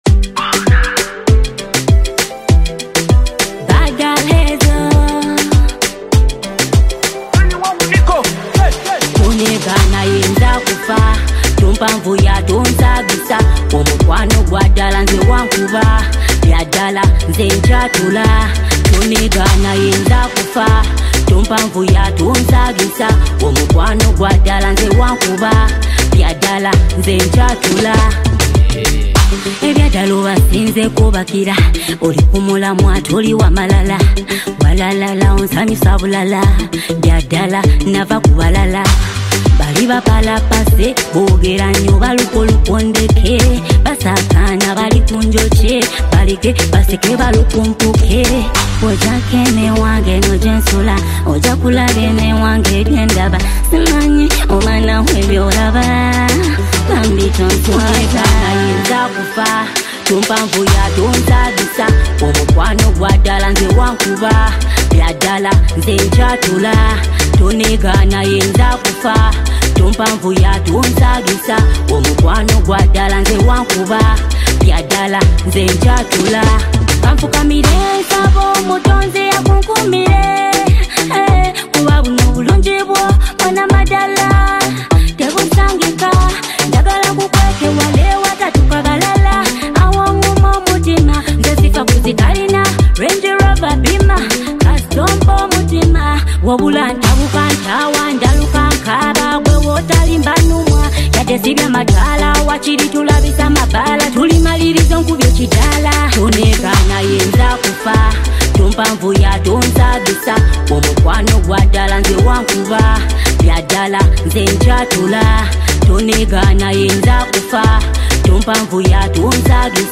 Genre: Afro-Beats